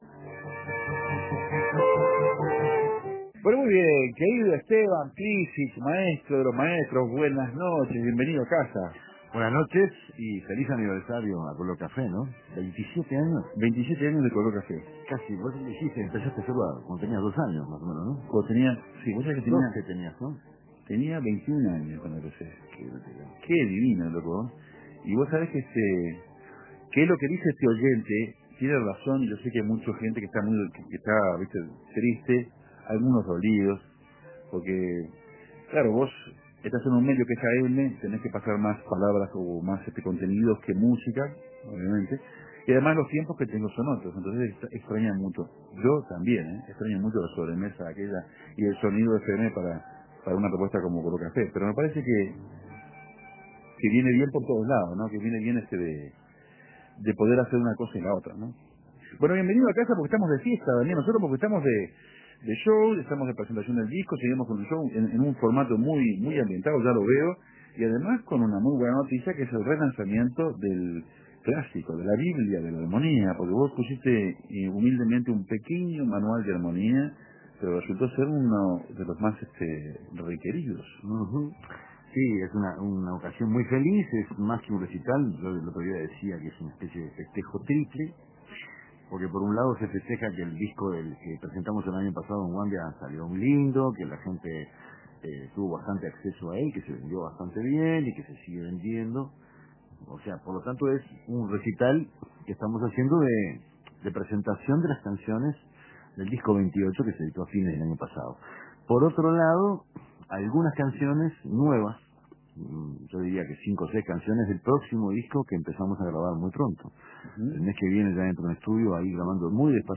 La fonoplatea colmó el estudio
guitarra. Fonoplatea previa a la presentación en La Colmena.